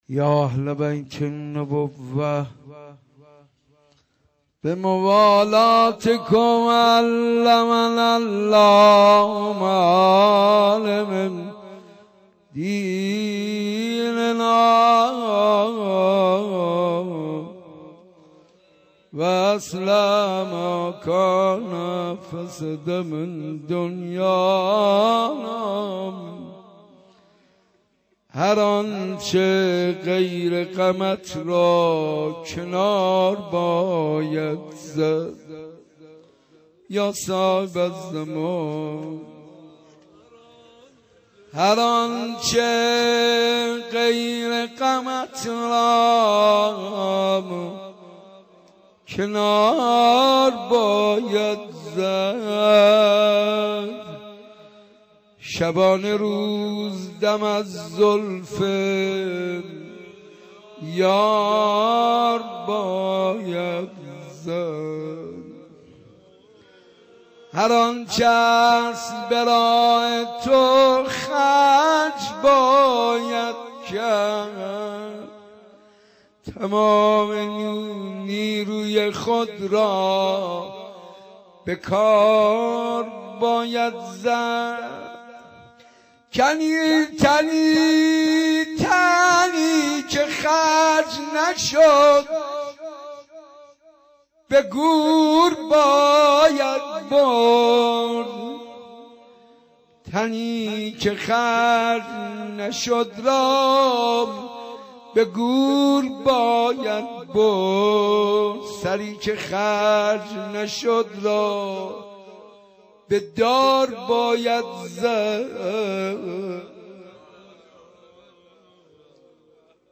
مراسم شب و روز شهادت حضرت معصومه(سلام الله علیها) در شهر مقدس قم با مداحی حاج منصور ارضی و حضور محبین و عزاداران اهل بیت(علیهم السلام) برگزار گردید.